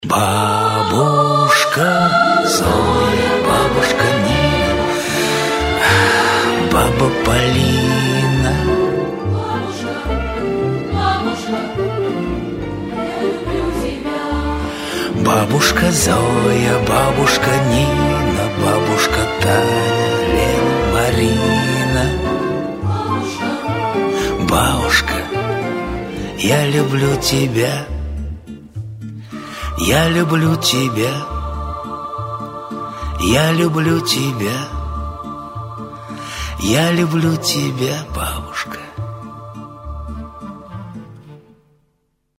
Хор
Душевные , Поп